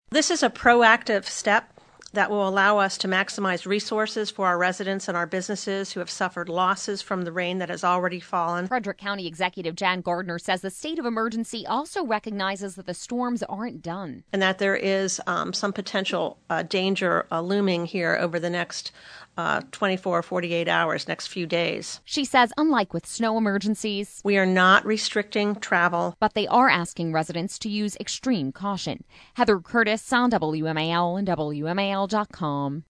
FREDERICK, Md. (WMAL) — Frederick County Executive Jan Gardner announced a state of emergency at a press conference on Thursday as the county faces more heavy rain and flooding.